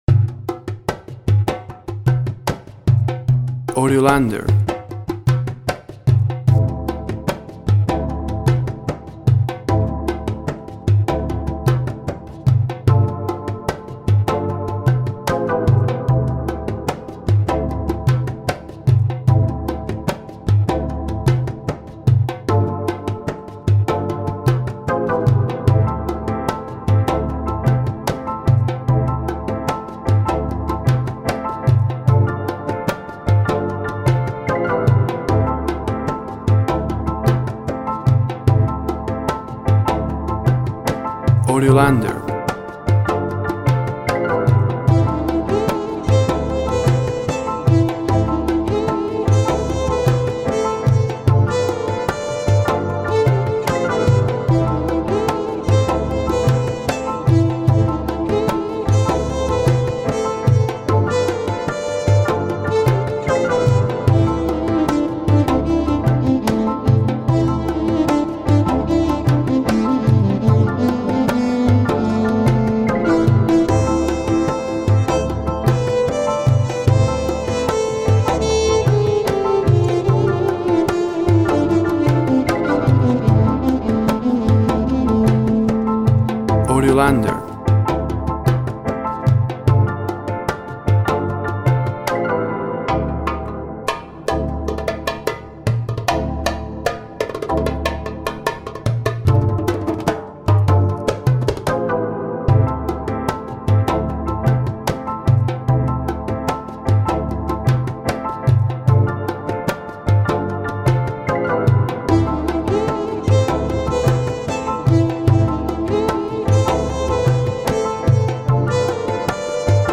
Solo piano work, melacolic sounds of calm.
Tempo (BPM) 75